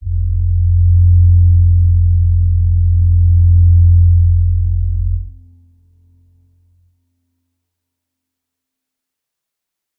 G_Crystal-F2-mf.wav